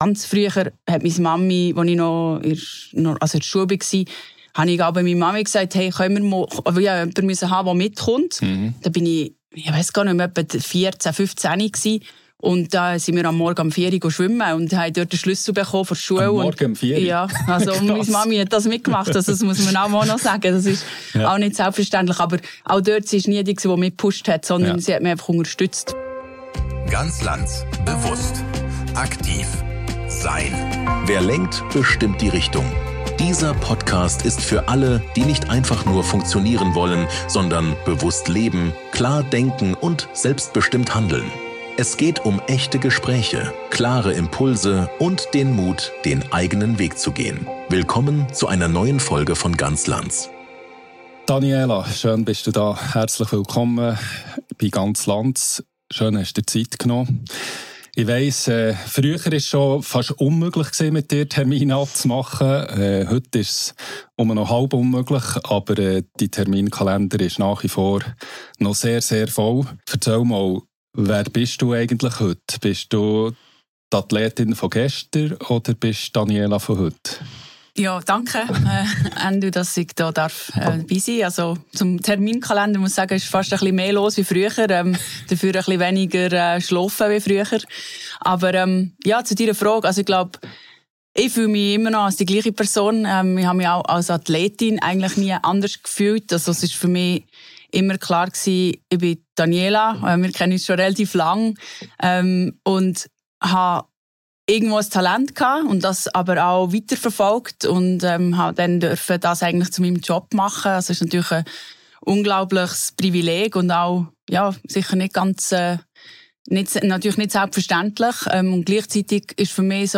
Ein ehrliches Gespräch über Leistung, Balance und das, was Weltklasse im Inneren wirklich bedeutet.